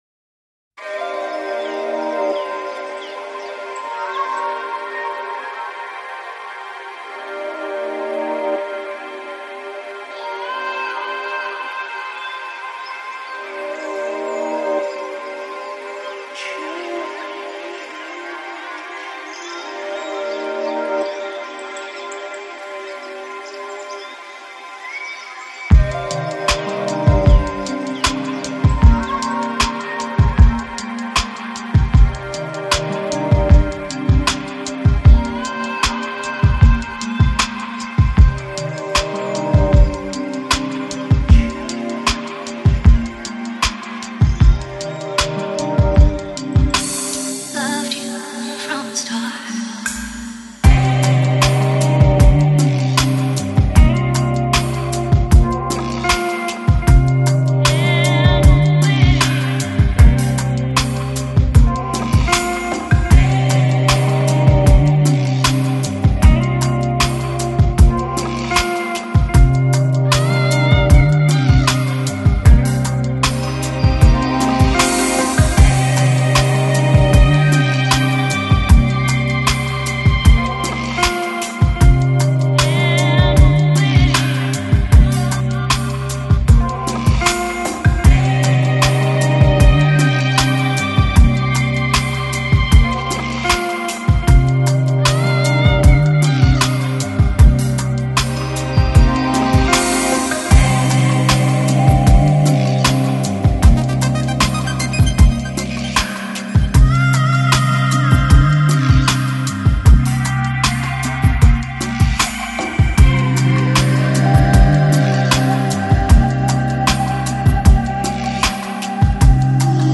Жанр: Lounge Future Jazz Downtempo